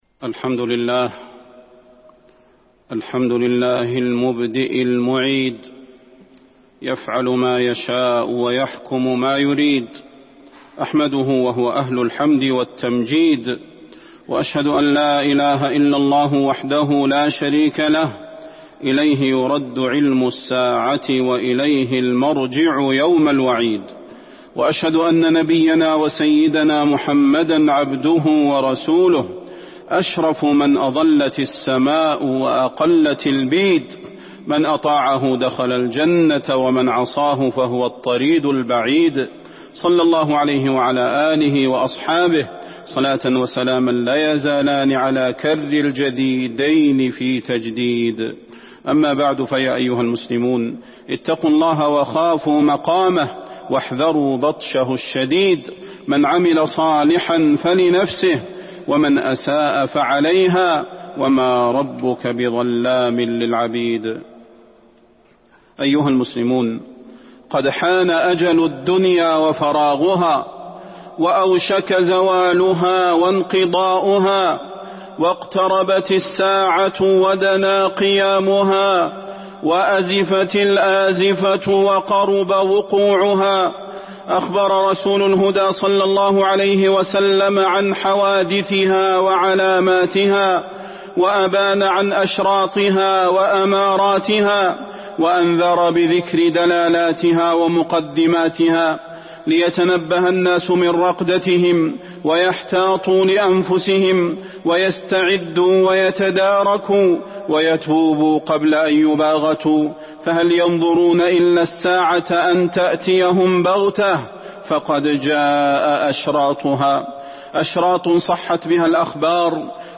تاريخ النشر ٢٠ ربيع الأول ١٤٤٢ هـ المكان: المسجد النبوي الشيخ: فضيلة الشيخ د. صلاح بن محمد البدير فضيلة الشيخ د. صلاح بن محمد البدير أشراط الساعة